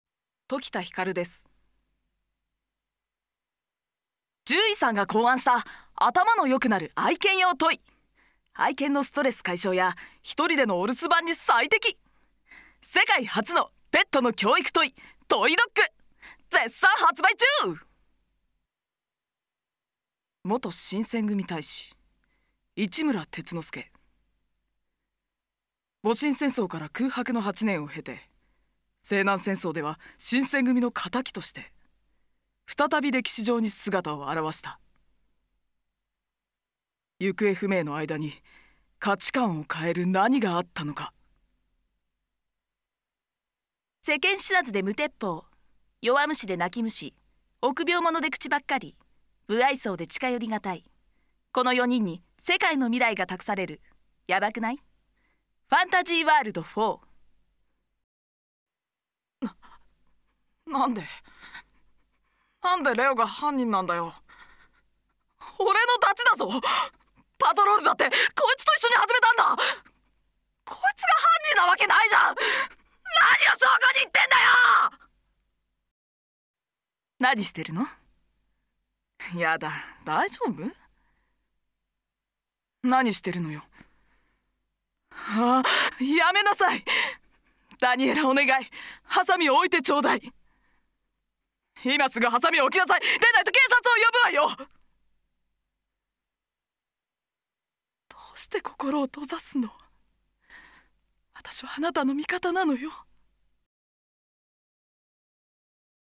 Voice Sample